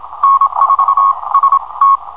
CW Hum
I'm in QSO with this Russian with a bad case of 50 Hz hum. Doesn't come through as pronounced on this sound clip, but still quite distinctive.
hum_cw.wav